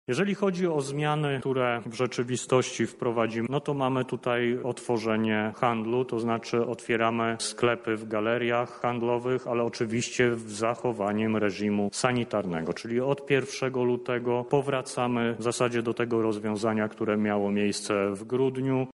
Podjęliśmy decyzję, która sprowadza się do przedłużenia etapu odpowiedzialności do 14 lutego – informuje minister zdrowia Adam Niedzielski.